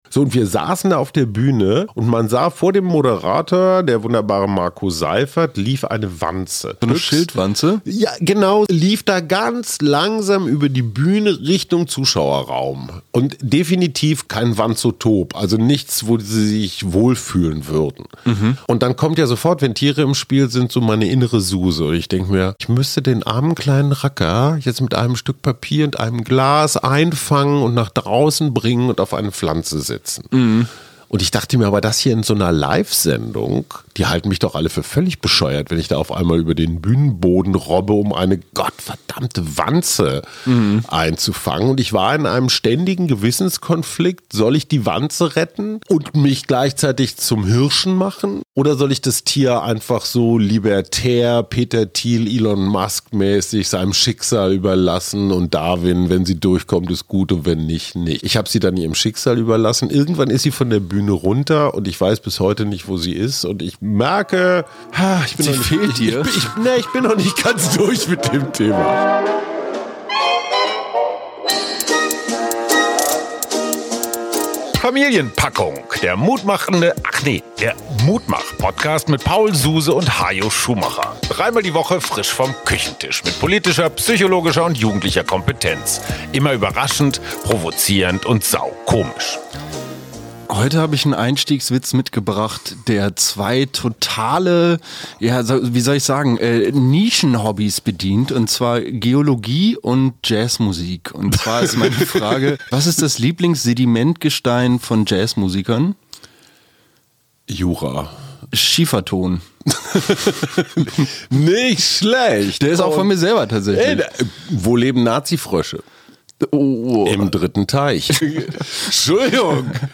Aus dem Schöneberger Hinterhofstudio